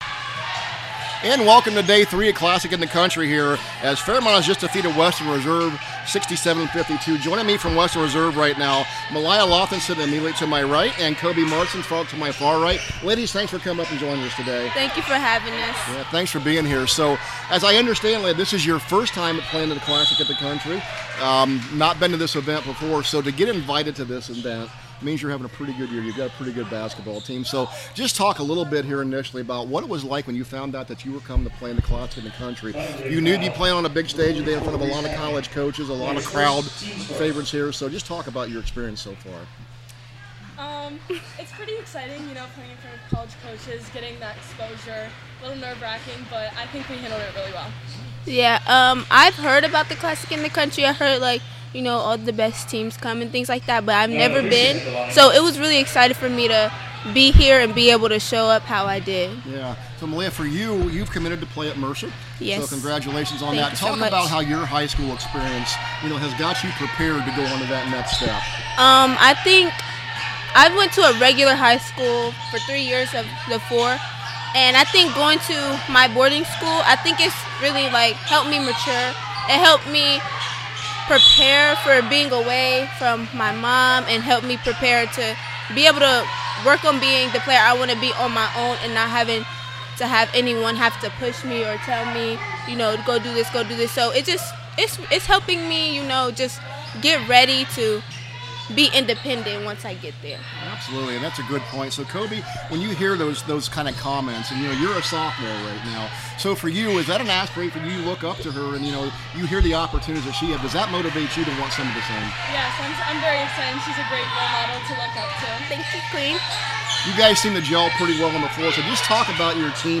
CLASSIC 2026 – WESTERN RESERVE INTERVIEW